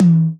Index of dough-samples/ uzu-drumkit/ ht/